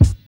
Kicks
[ACD] - SamuraiChamploo Kick.wav